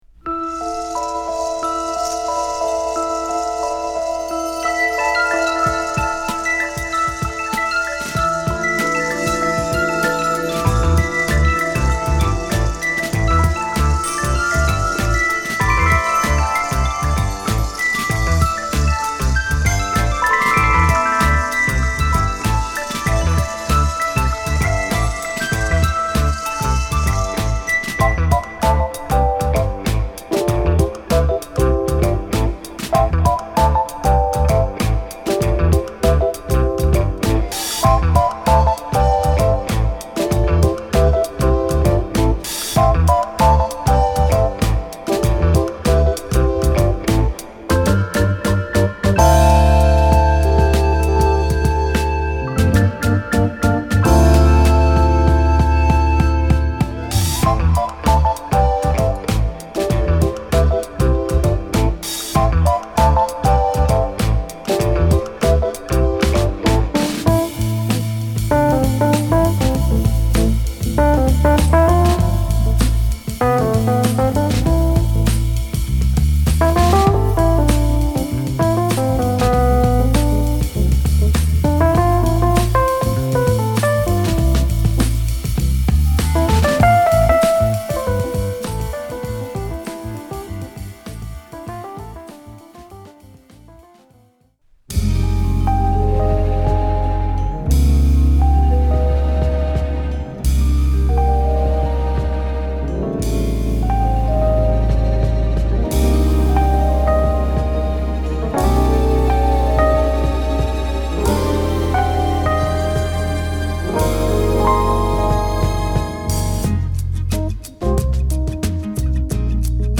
浮遊感マンテンのエレピにスリリングなベースラインが印象的なキラーチューン！